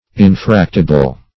Infractible \In*fract"i*ble\, a. Capable of being broken.